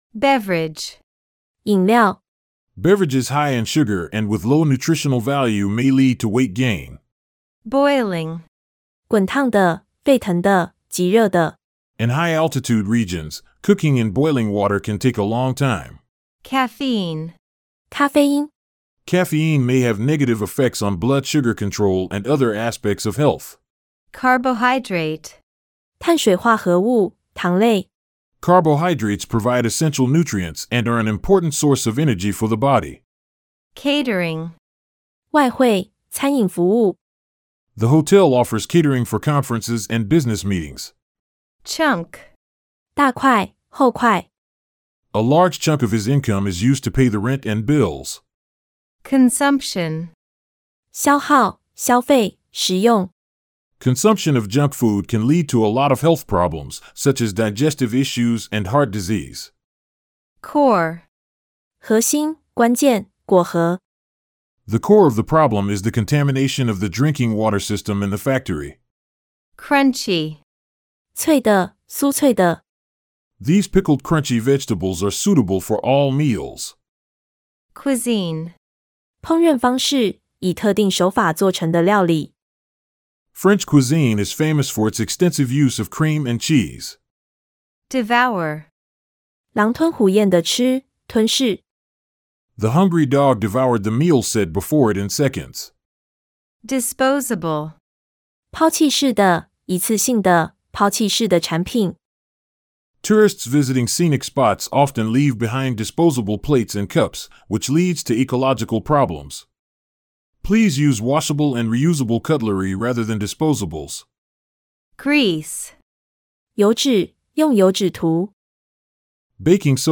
★ 多版本 MP3 學習音檔，純正美式口音，邊聽邊學、加深印象最有效！
道地美式發音，清楚易學，配合書中音標標記，一邊看一邊開口跟著唸，不只看得懂，還能聽懂開口說！